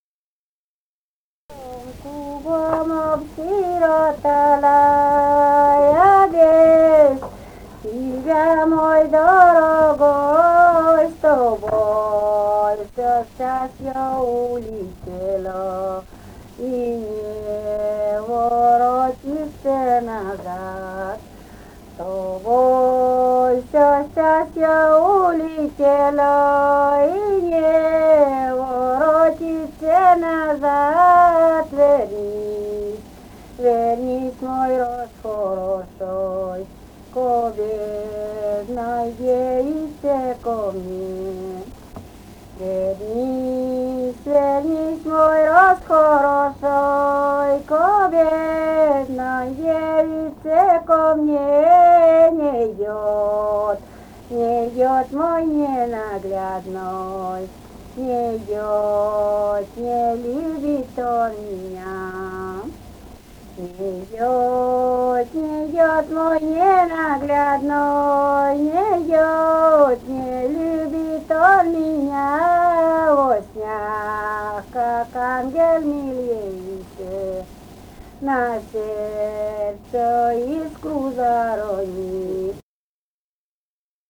Живые голоса прошлого 016. «Кругом, кругом осиротала» (лирическая).